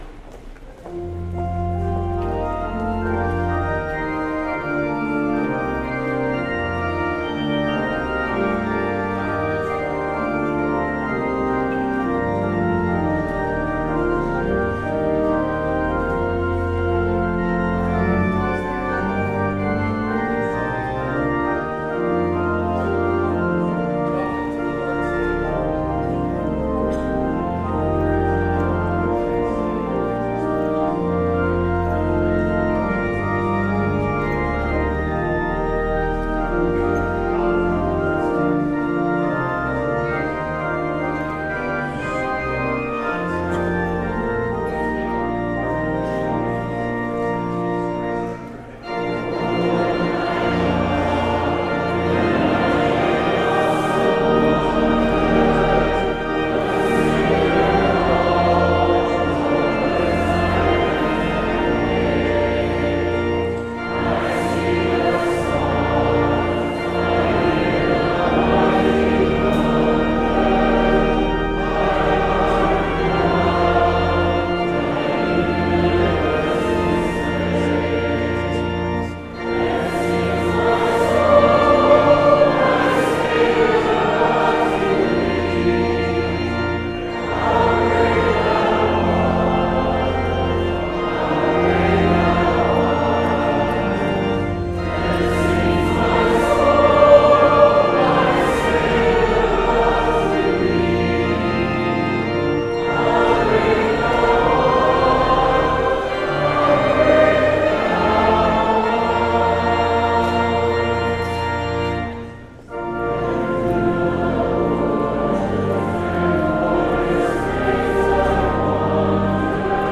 8:30 a.m. service St. John Adult Choir
organist
strings